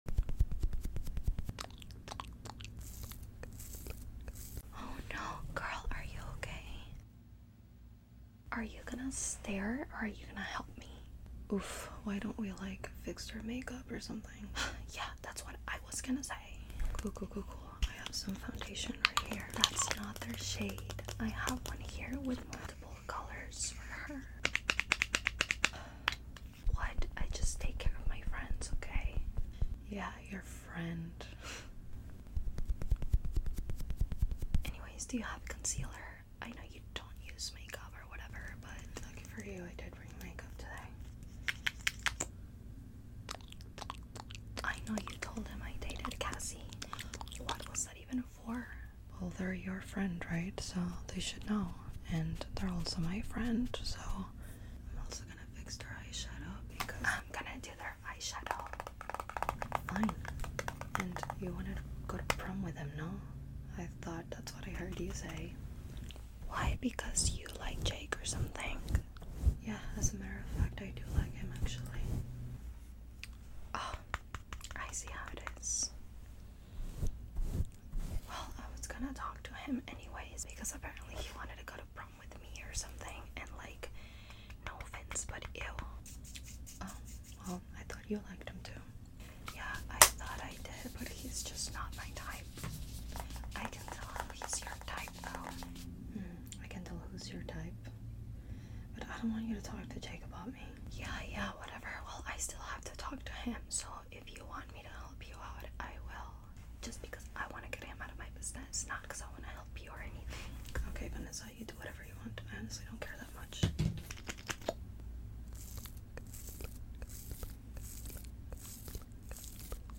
ASMR the mean girl that sound effects free download